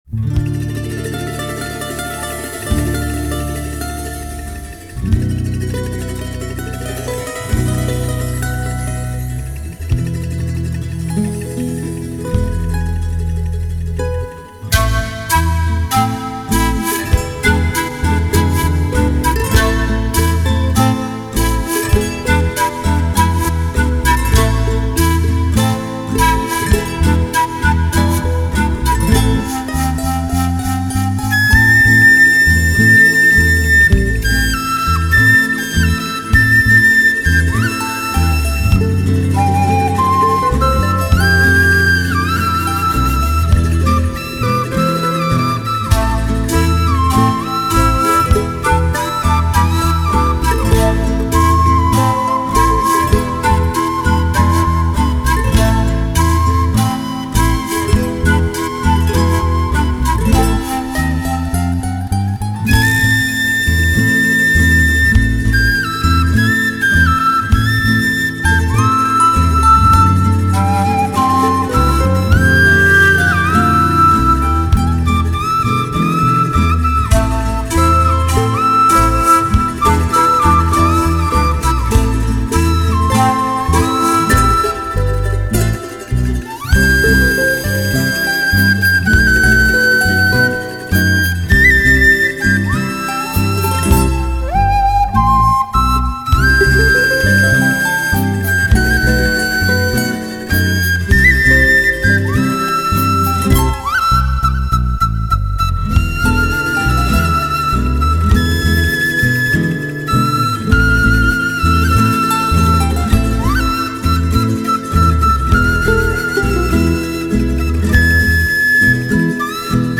Panpipes.